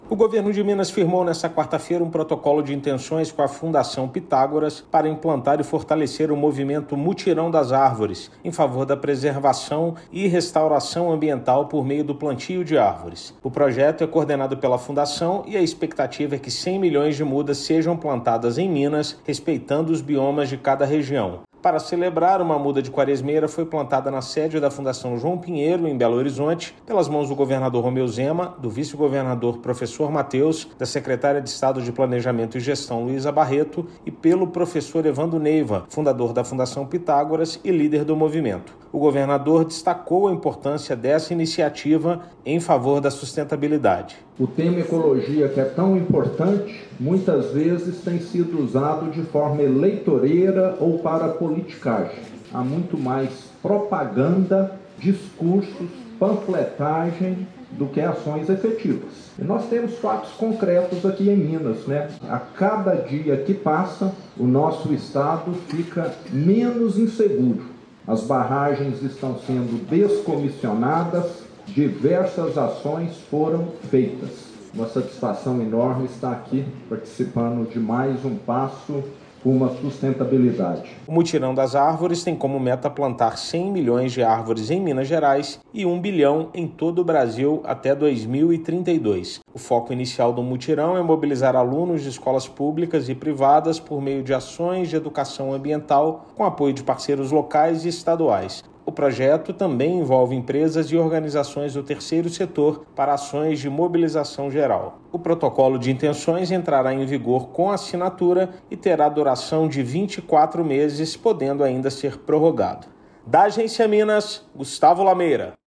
Parceria prevê ações de mobilização e conscientização para fortalecer a preservação ambiental. Ouça matéria de rádio: